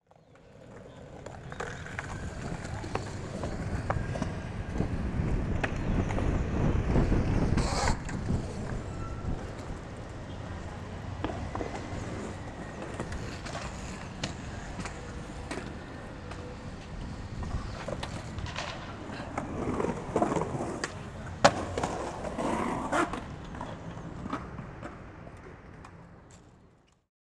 ambiente skate viento.wav
HOLOFONIKA FOLEY MEDELLIN 2013 es una apuesta desde lo sonoro para ayudar y fortalecer la escena Audiovisual de Medellin , creando así un banco de sonidos propios de la ciudad, que pueden ser utilizados para el diseño sonoro, arte o simplemente para tener una memoria sonora de una ciudad.